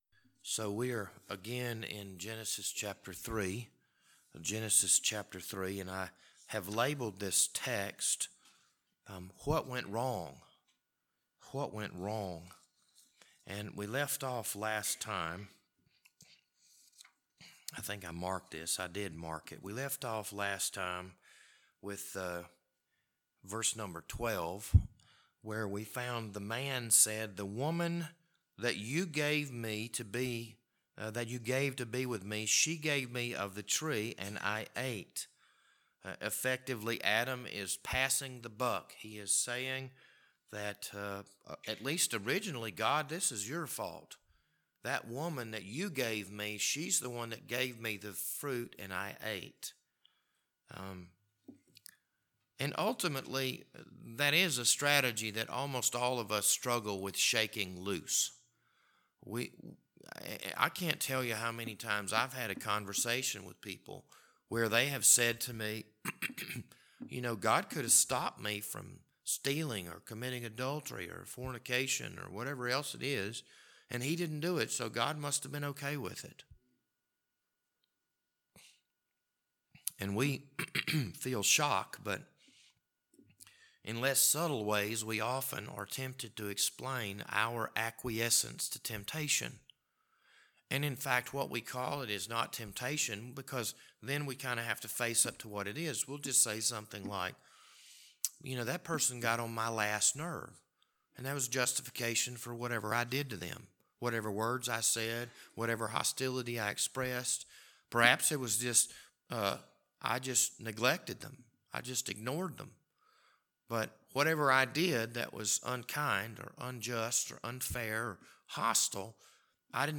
This Wednesday evening Bible study was recorded on April 27th, 2022.